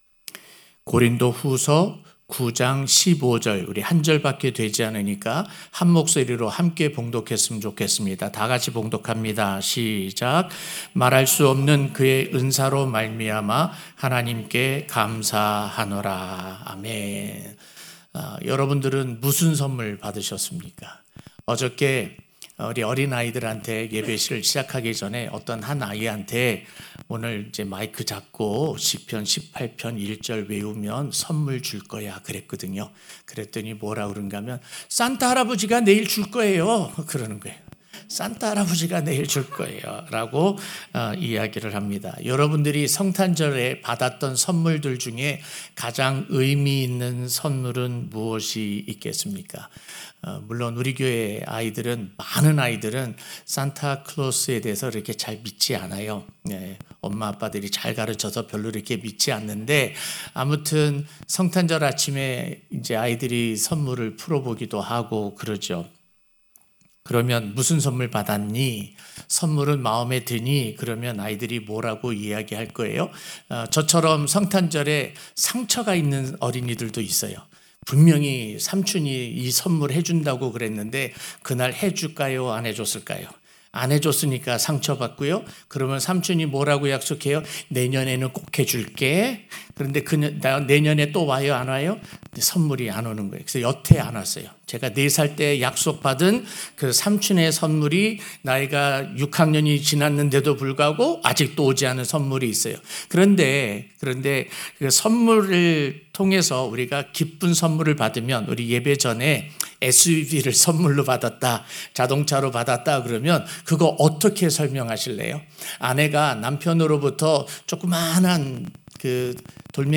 성탄절예배 설교: 표현할 수 없는 그의 선물(고후 9:15)